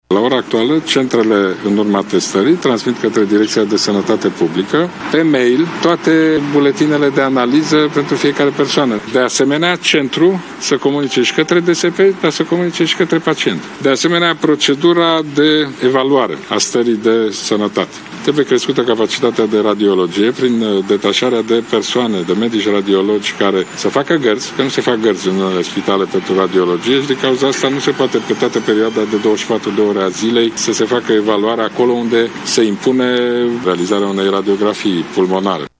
În urma unei vizite realizate la o Direcție de Sănătate Publică, premierul Ludovic Orban a declarat că sunt în discuție diverse soluții pentru a urgenta anchetele epidemiologice.
Prim ministrul, Ludovic Orban: